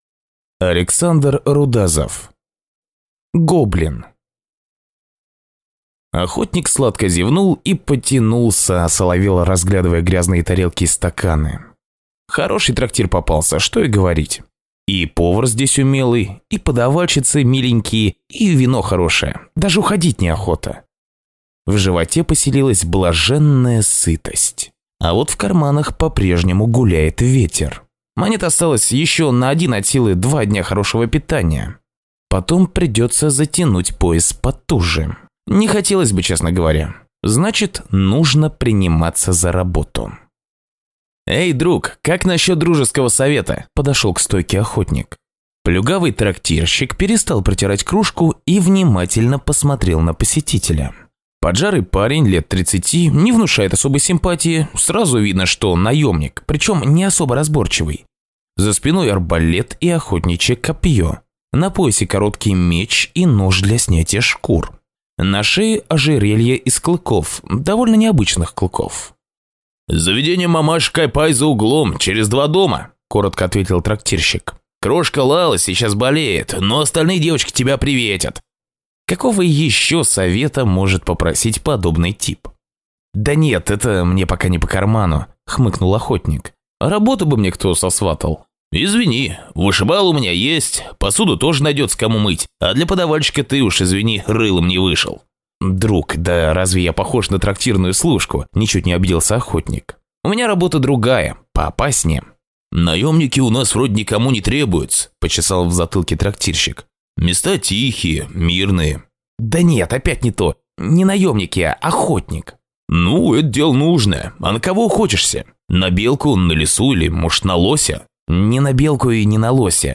Аудиокнига Гоблин | Библиотека аудиокниг